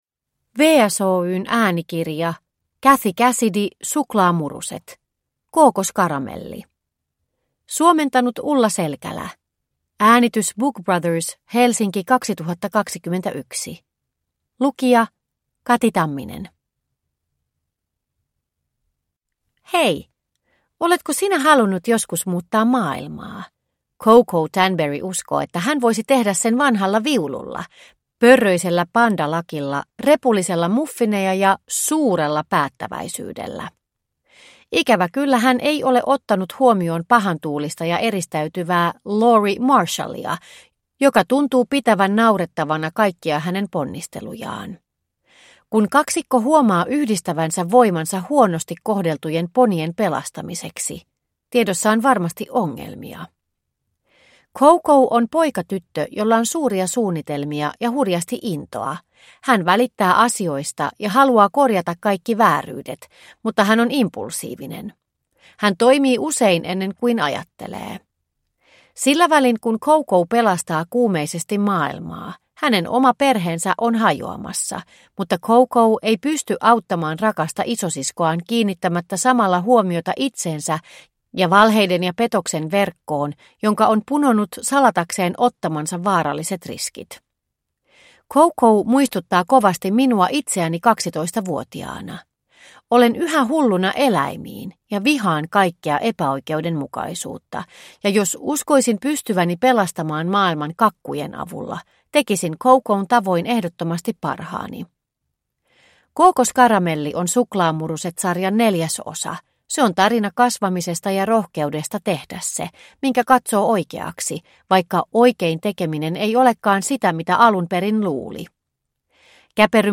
Kookoskaramelli (ljudbok) av Cathy Cassidy